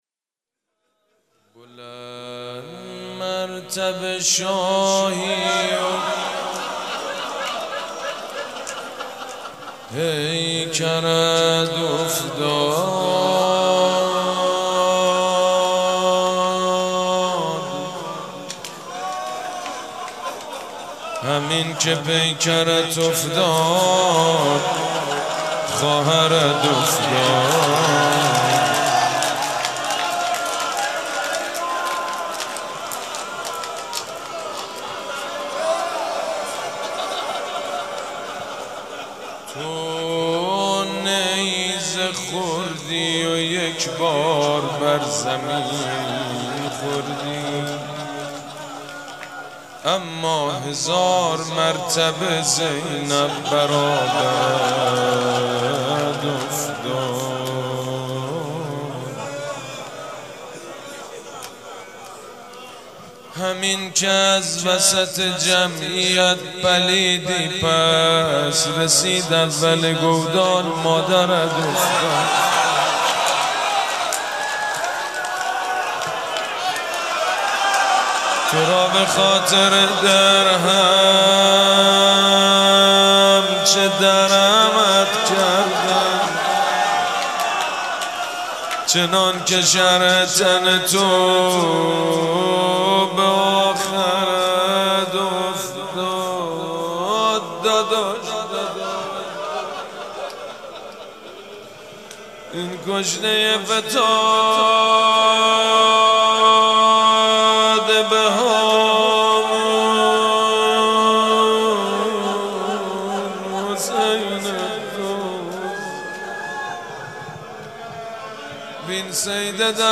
روضه
مـراسـم سیاه پوشان دوشنبه ۲۷ شهریور ماه ١٣٩۶
مداح حاج سید مجید بنی فاطمه